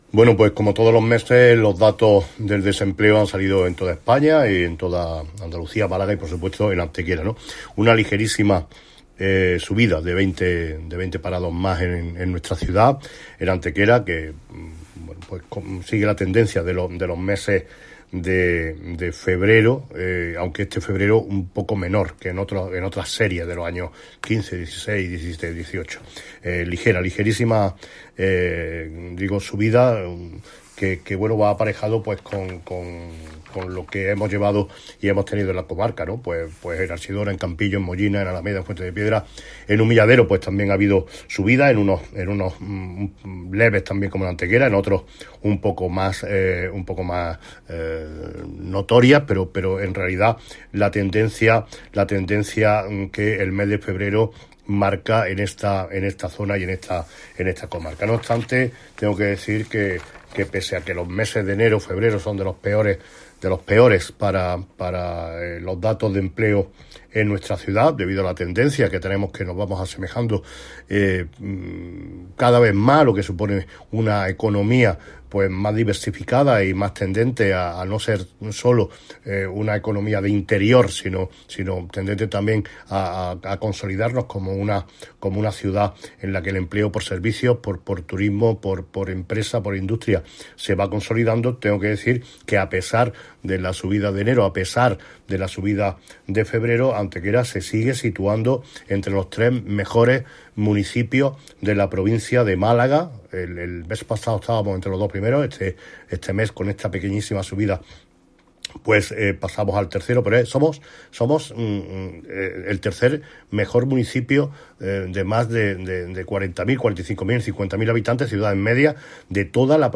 Generar Pdf martes 3 de marzo de 2020 Valoración de los datos del desempleo del mes de febrero en Antequera Generar Pdf AUDIO Corte de audio del alcalde Manolo Barón con declaraciones valorando los datos del desempleo del mes de febrero. Cortes de voz M. Barón 1778.21 kb Formato: mp3